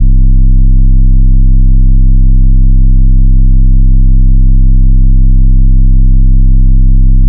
DDW Bass 1.wav